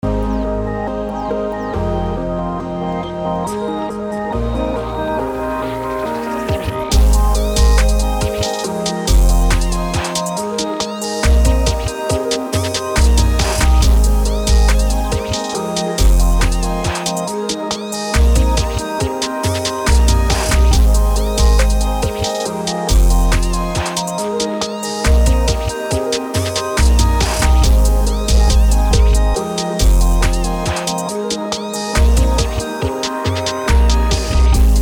BPM: 139
Key: F minor
Beat preview